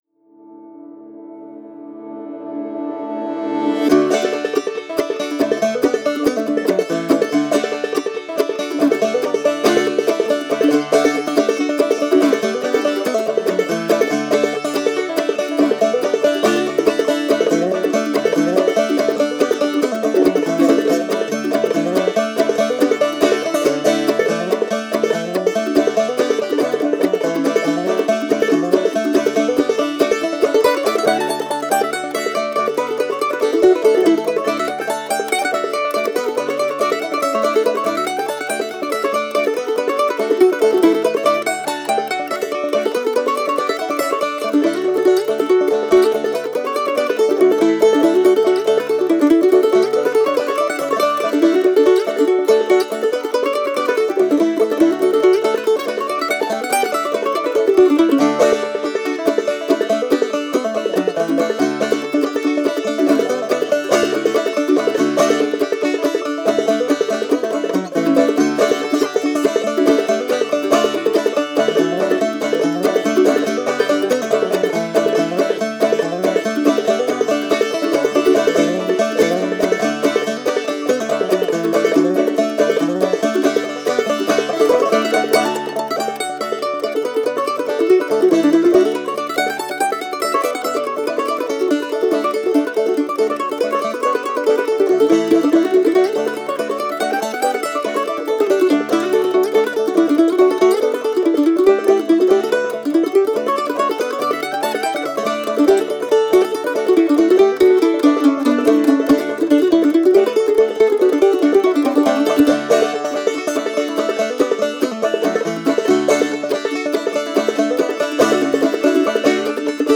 Genre: Bluegrass, Americana, Folk